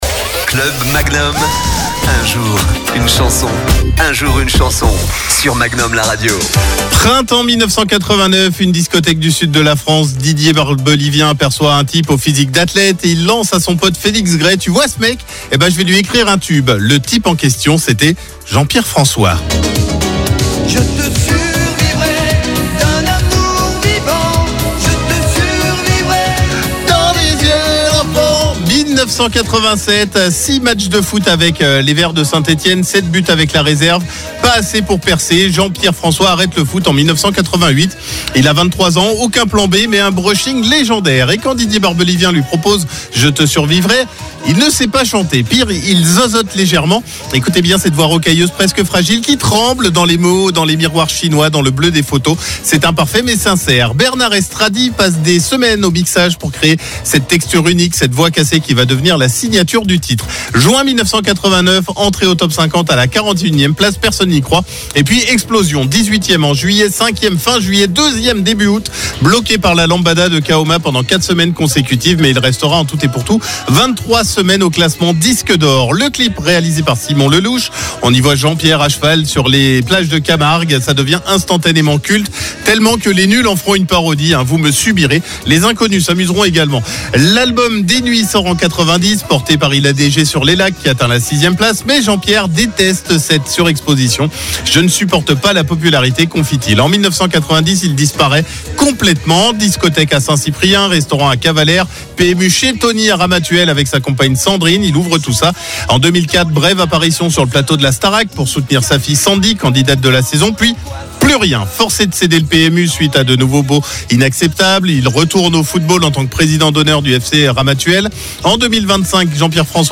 Années 80